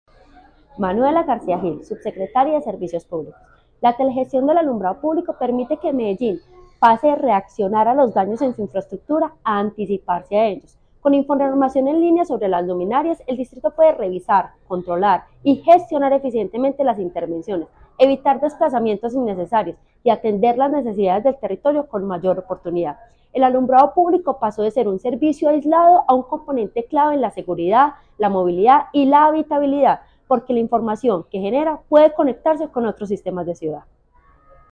Declaraciones de la subsecretaria de Servicios Públicos, Manuela García Gil La Administración Distrital avanza en la modernización del alumbrado público de Medellín y contabiliza hasta el momento 2.218 luminarias inteligentes instaladas este año.